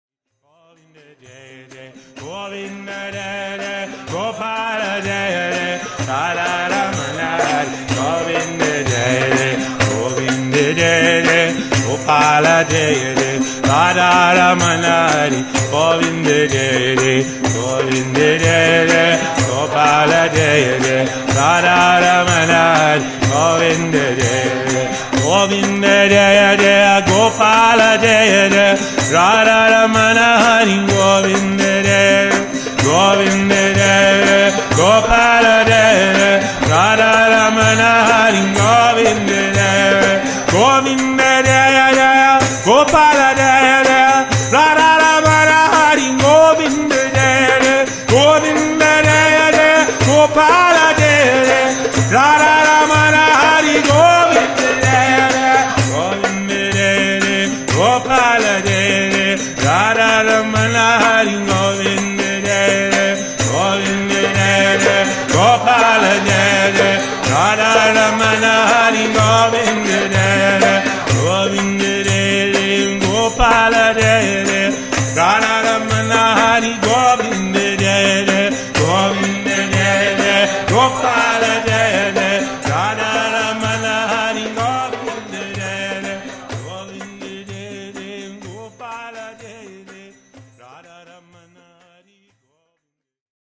Live Kirtan Chanting CD
This is a live recording of one these Kirtans.